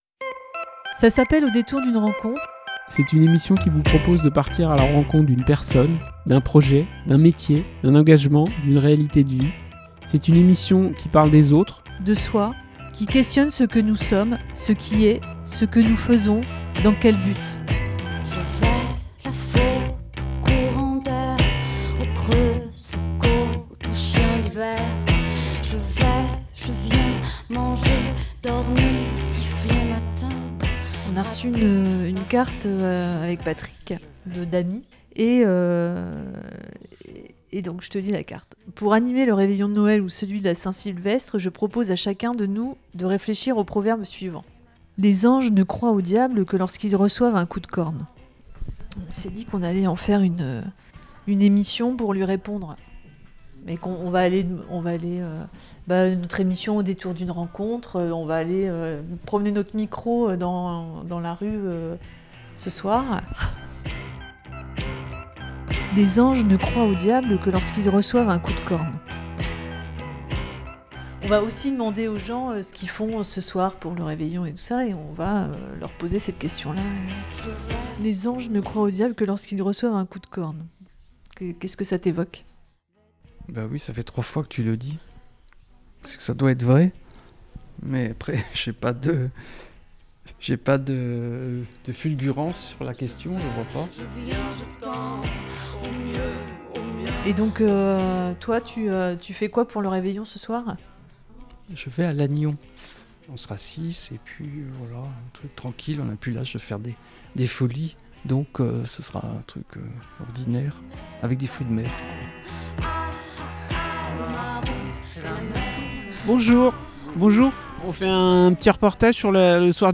Au détour proverbe et d'un réveillon, nous avons rencontré des personnes dans les rues de Quimper ....
N'ayant pas de réveillon de programmé, nous avons eu l'idée d'interroger les passants au sujet de cette sentence. Nous avons donc passé une soirée de la Saint sylvestre d'itinérance dans le centre ville de Quimper... nous avons rencontré des gens debout, assis, en chemin... des gens seuls ou en famille ou entre amis... des gens d'ici, des gens d’ailleurs... des gens prêts pour le réveillon ou qui s'étaient trompés de jour. Tous ont joué le jeu de la petite énigme, proposant des significations plutôt simples ou complètement extravagantes.